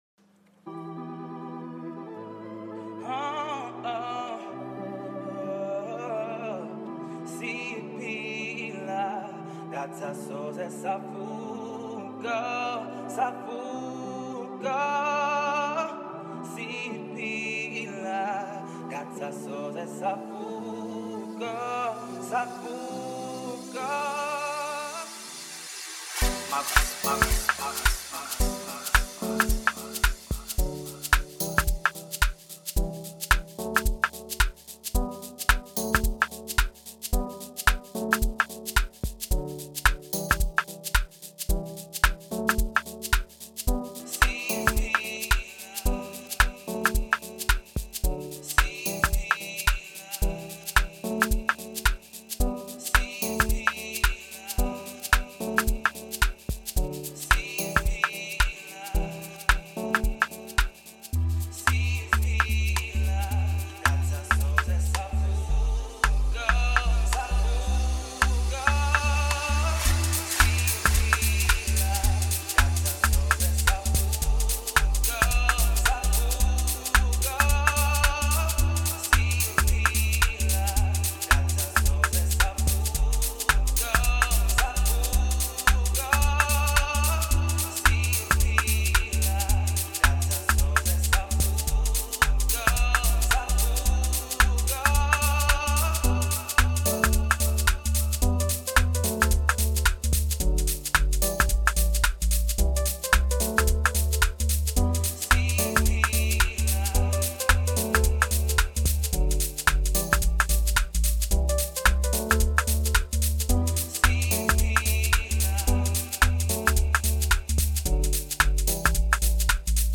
05:23 Genre : Deep House Size